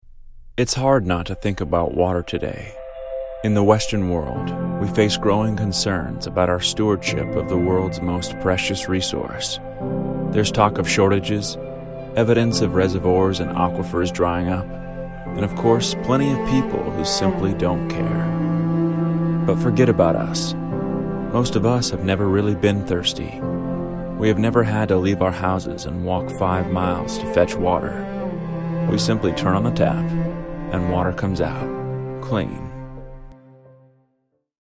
Anglais (Américain)
Commerciale, Naturelle, Fiable, Chaude
Vidéo explicative